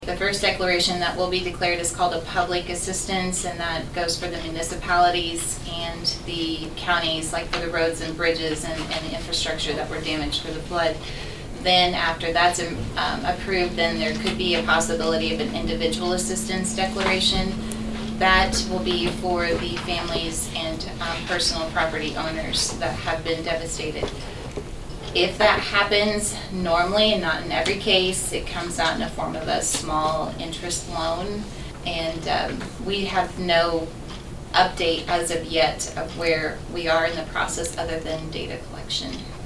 At the meeting of the Saline County Commission on Thursday, August 5, Northern District Commissioner Stephanie Gooden gave an update on the county’s efforts to be declared a disaster area due to the devastating flooding the county suffered at the end of June.